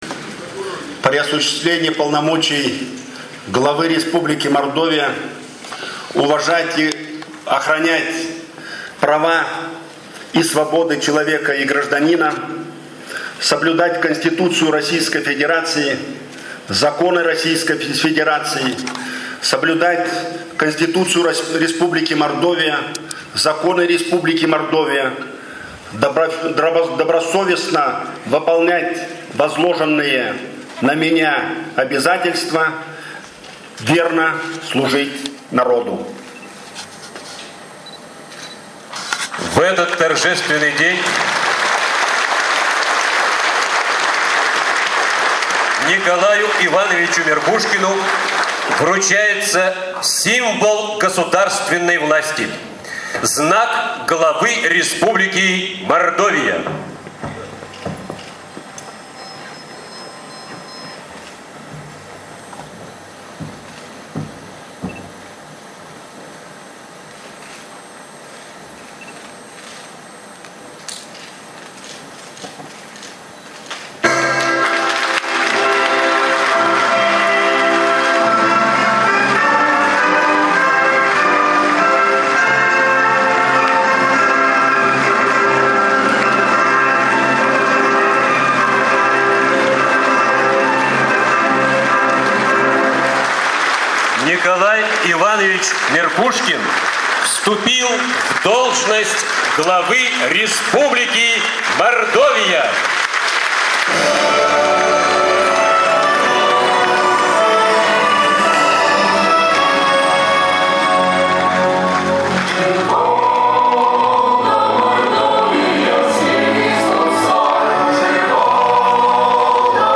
На торжественной церемонии, состоявшей в республиканском дворце культуры, Николай Иванович Меркушкин зачитал текст присяги, после чего председатель Госсобрания Мордовии Валентин Конаков вручил ему символ государственной власти – знак главы Республики Мордовия.
Речь Н.И. Меркушкина на инаугурации